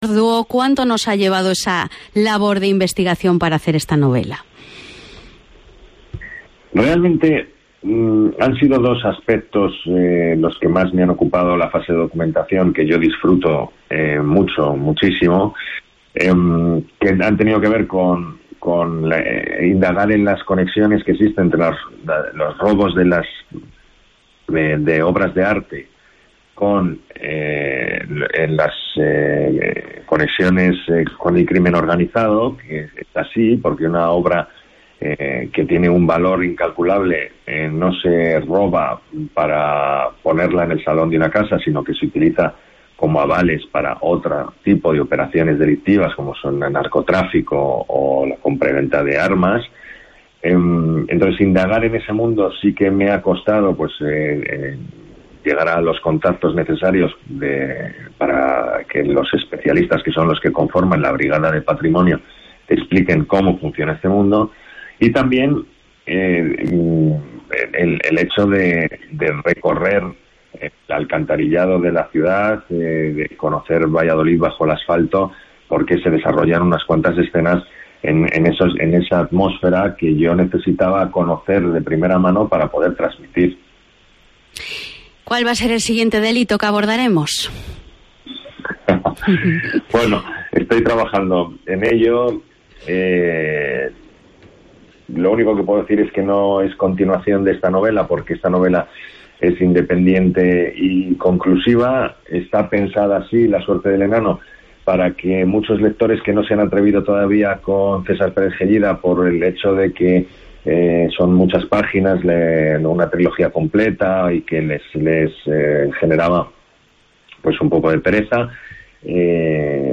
Informativo Mediodía en Cope Galicia 31/08/2021. De 14.48 a 14.58h